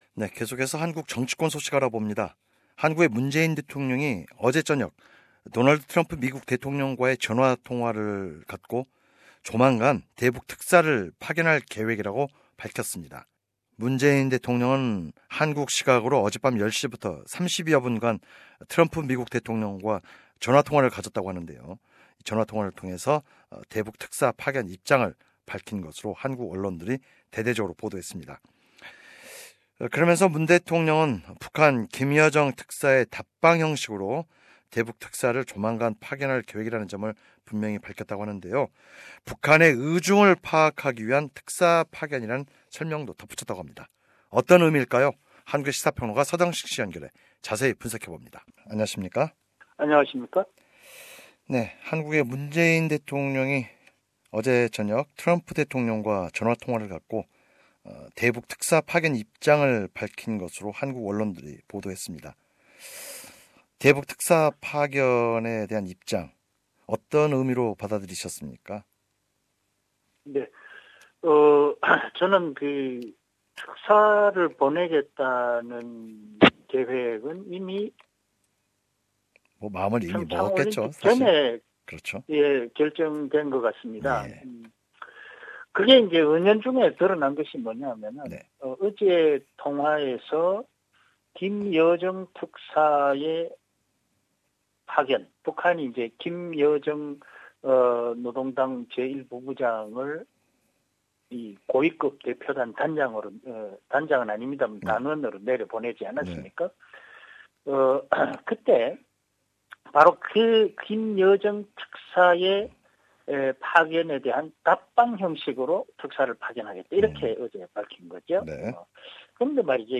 [상단의 포드캐스트 버튼을 누르시면 자세한 내용을 오디오 뉴스로 들으실 수 있습니다.]